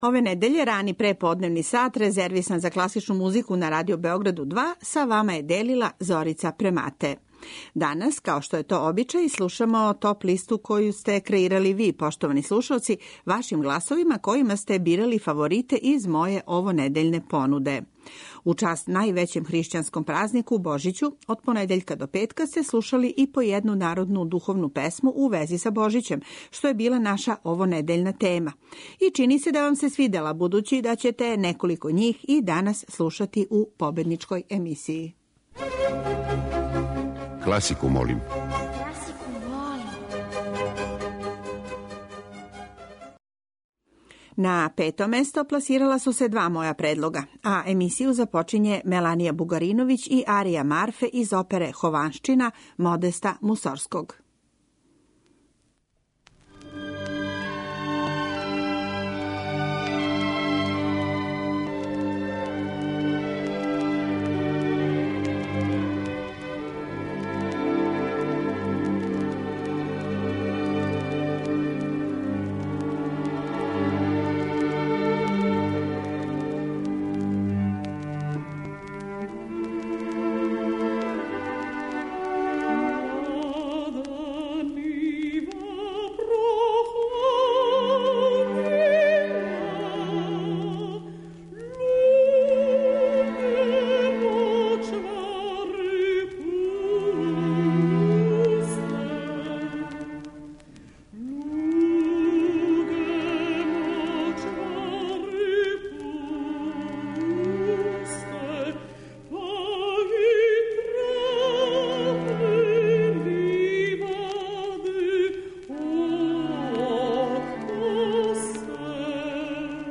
Данас слушамо топ-листу класичне музике за ову недељу.
Уз њих, могли сте да чујете и хитове класичне музике, али и мање позната дела из пера композитора свих епоха, од анонимних аутора ренесансе, па до 21. века и најновијих остварења српских композитора.